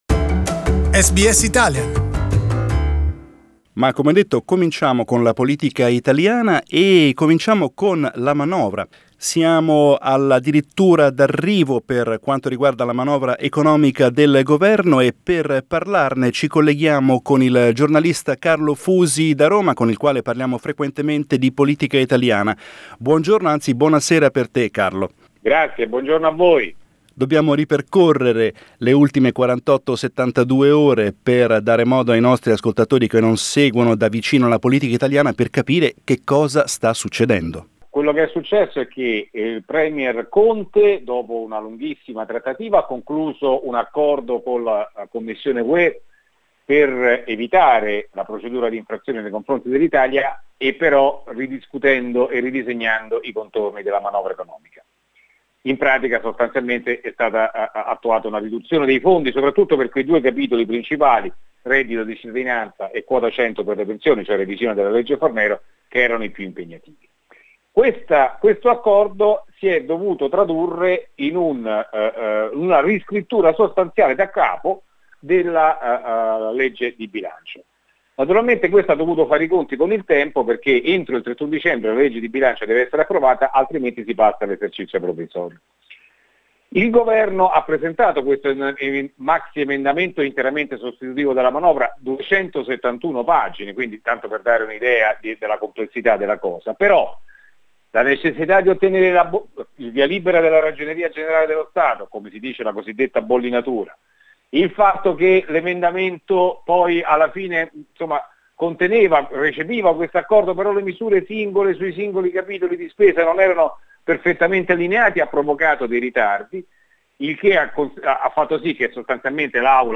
Our analysis with journalist